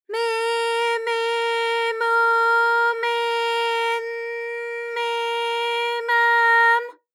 ALYS-DB-001-JPN - First Japanese UTAU vocal library of ALYS.
me_me_mo_me_n_me_ma_m.wav